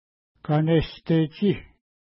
Pronunciation: ka:nestetʃi: